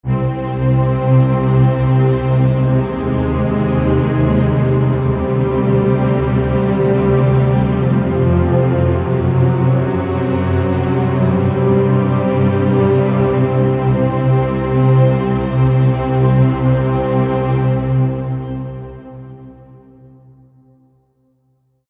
Das zieht eine Bandbreite von eher traditionellen bis hin zu sehr experimentellen Streicherklängen mit sich.
Klangbeispiel Preset „The Big Picture“